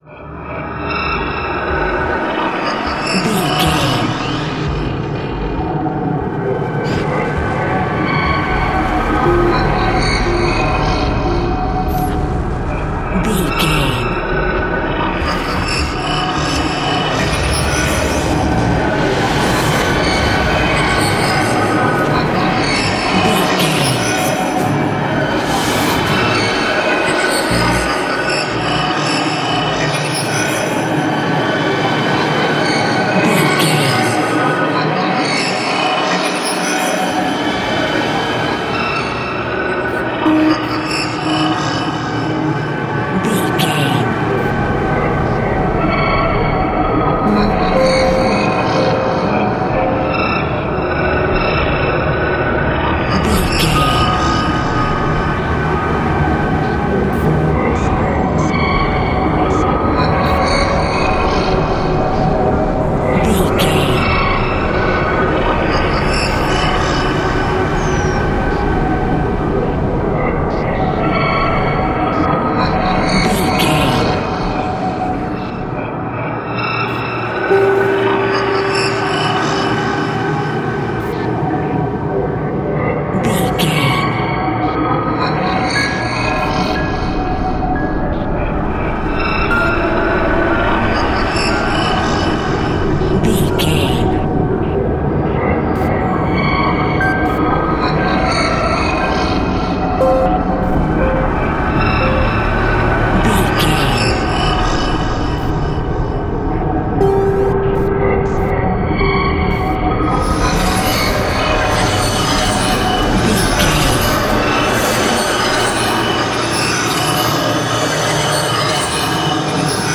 Atonal
Slow
scary
tension
ominous
dark
suspense
haunting
eerie
synthesiser
horror
keyboards
ambience
pads